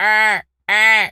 seagul_squawk_deep_04.wav